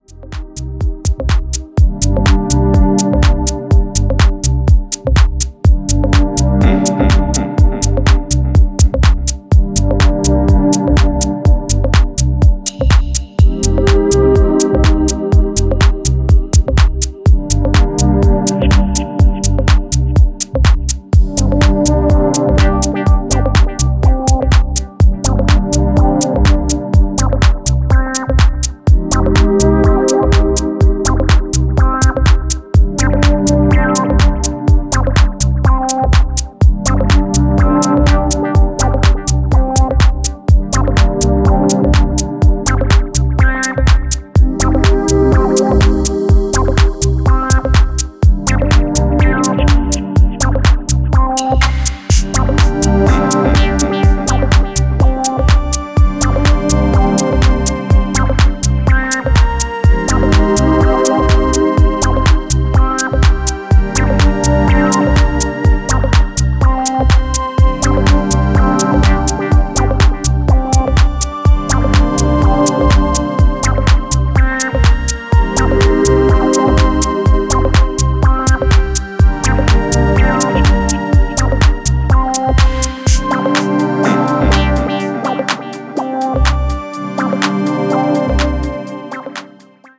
優美なコードとアシッディーなリード等が織りなす巧みなレイヤーに息を飲む
秀逸なミニマル・ハウス群を展開しています。